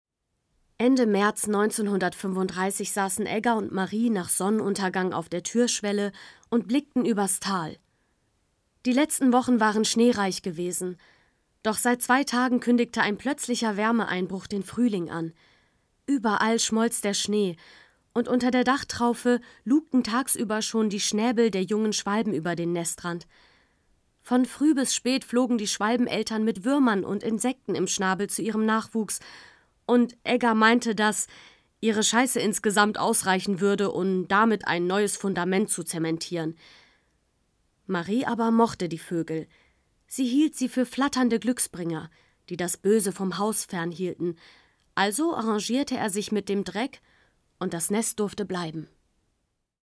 dunkel, sonor, souverän, hell, fein, zart, markant, sehr variabel
Mittel minus (25-45)
Norddeutsch
Erzählung